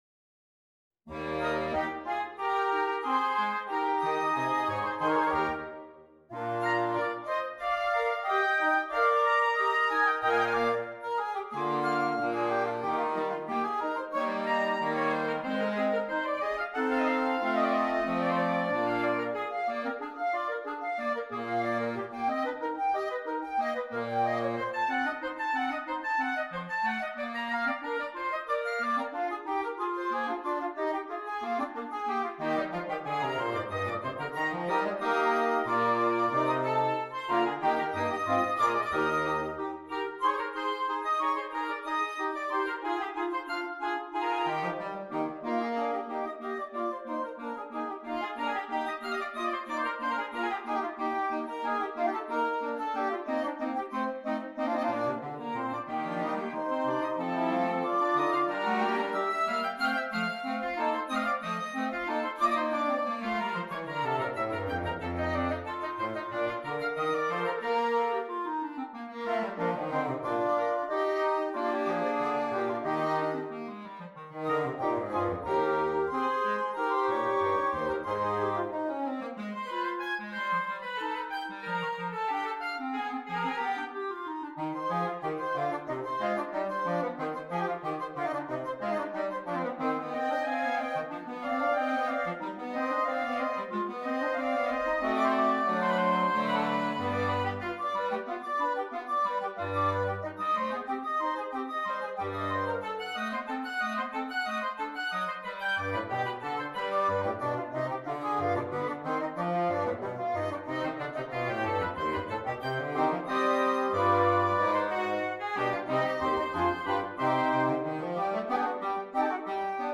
Voicing: Flexible Woodwind Quintet